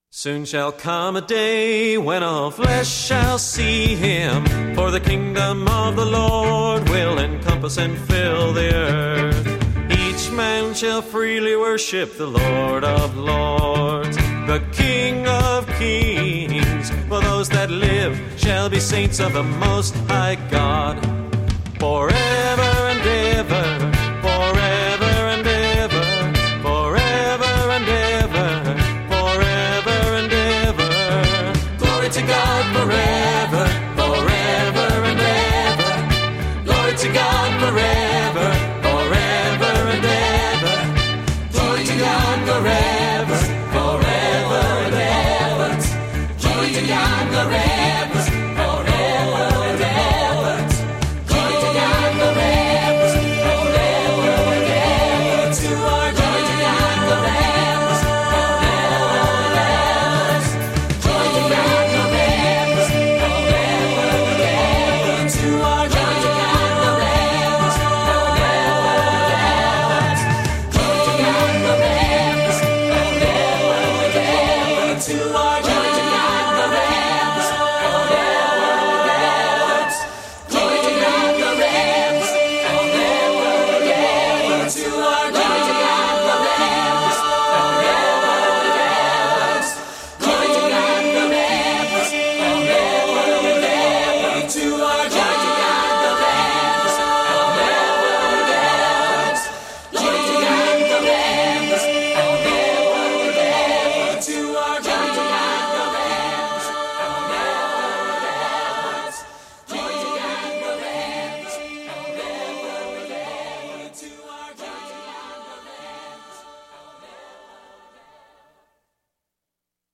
Piano
Electric & acoustic guitars
Bass guitar
Percussion
Trumpet
English horn, saxophone and clarinet
Background vocals